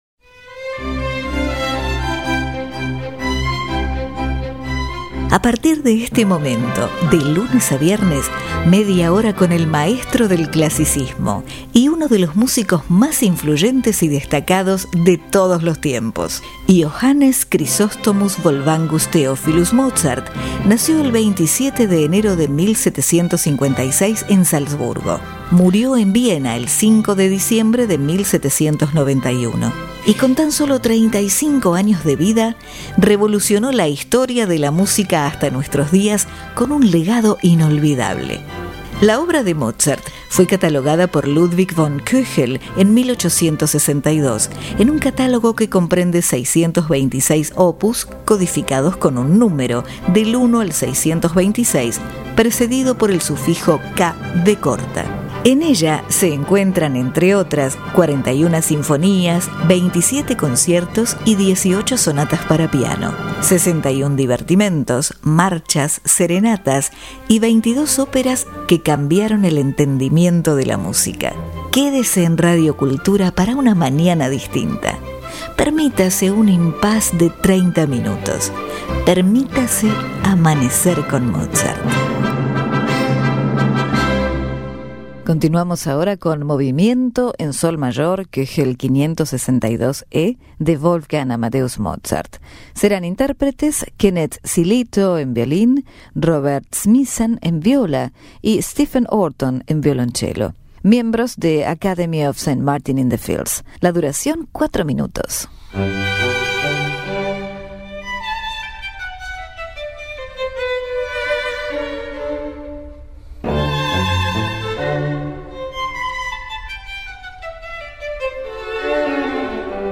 Sonata En Fa Mayor
Andante Para Flauta Y Orquesta En Do Mayor